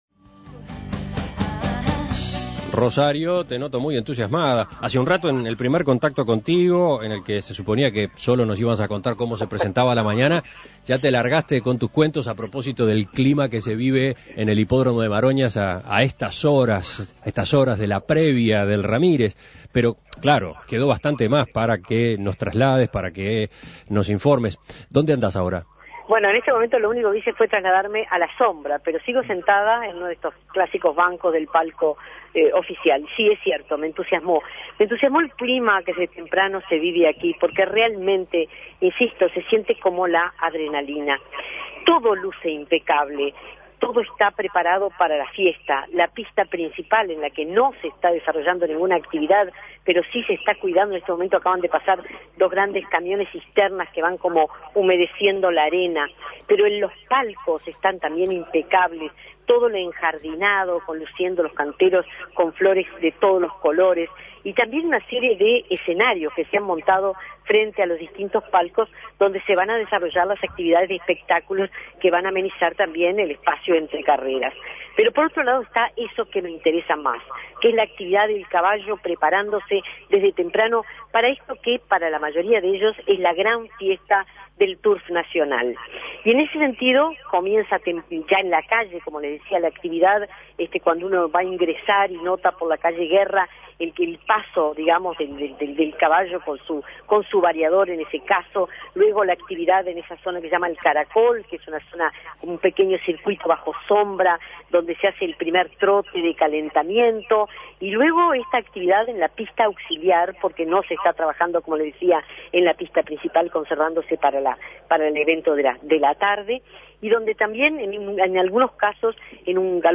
El clima en el Hipódromo de Maroñas en las horas previas a las carreras